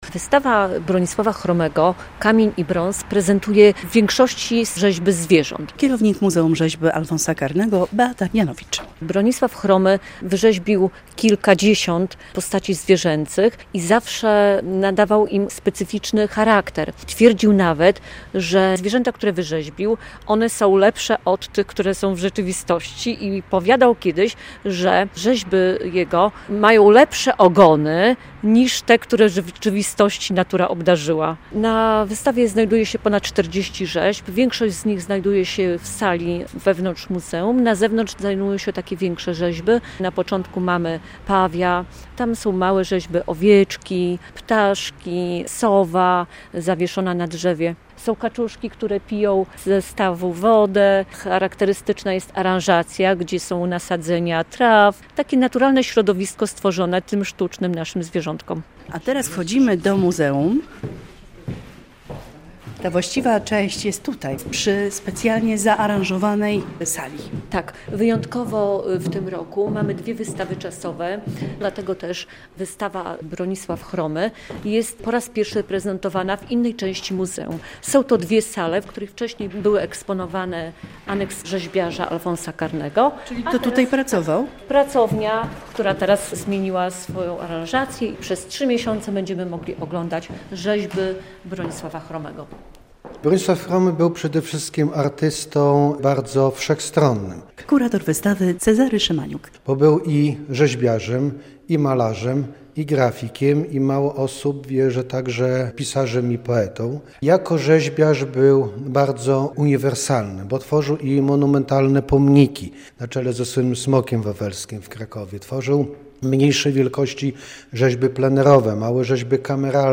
W Białymstoku można zobaczyć rzeźby Bronisława Chromego - relacja